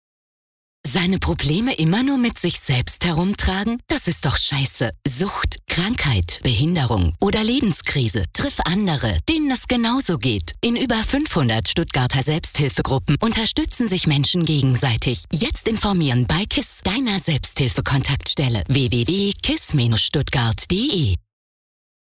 Parallel dazu haben wir durch die finanzielle Unterstützung der IKK Classic einen Radiospot für Antenne 1 und Die Neue 107,7 produzieren können: > Radiospot hier anhören <
KISS-SPOT-SELBSTHILFEGRUPPE-V1-20.mp3